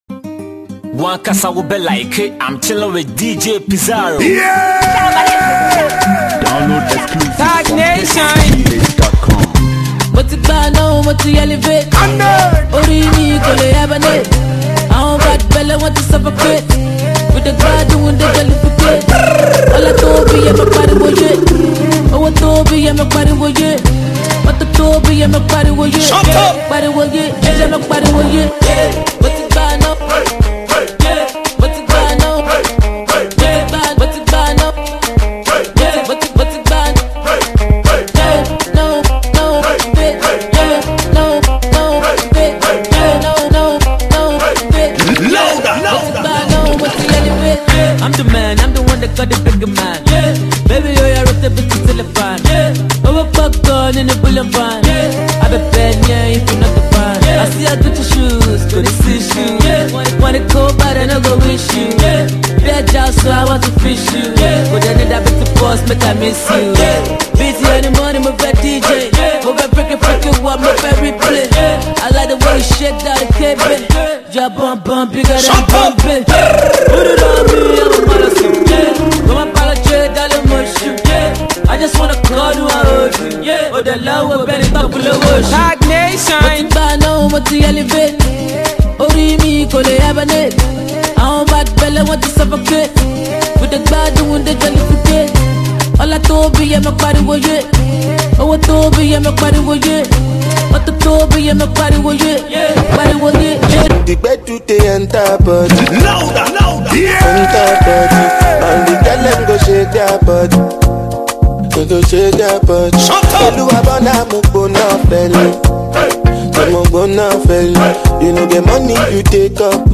Genre: Mixtape